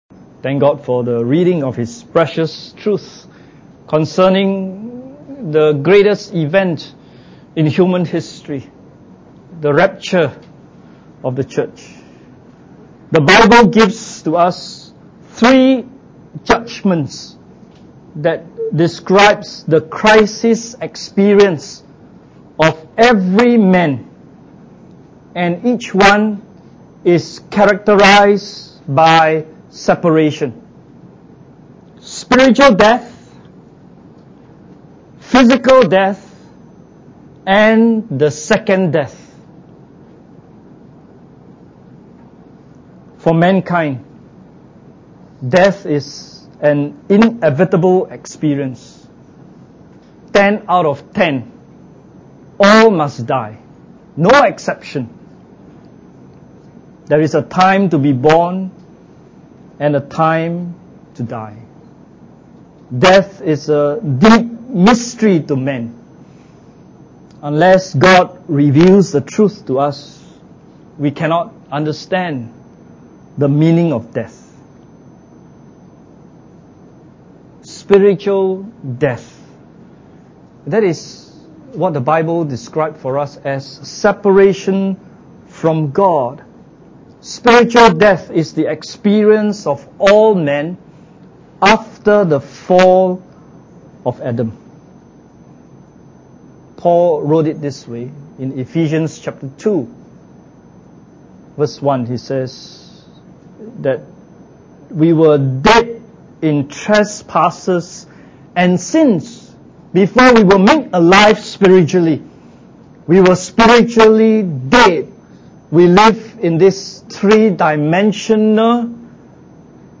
Church Camp 2015 Looking for That Blessed Hope – Rapture (We Shall Be Changed) Message 3